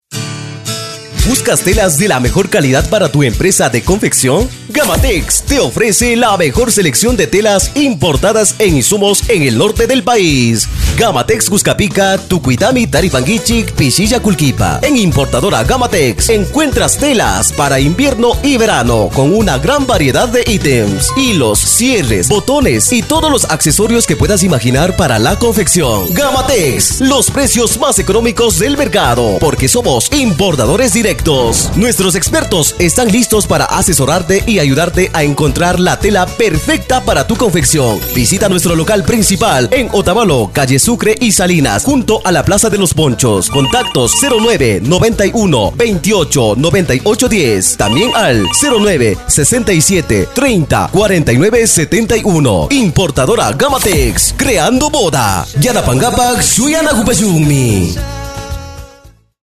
Grabación y Producción de cuñas | Radio Ilumán
En Radio Ilumán te ofrecemos el servicio profesional de grabación de cuñas radiales en dos idiomas: kichwa y castellano, con locutores y locutoras que comunican con autenticidad, claridad y cercanía a la audiencia.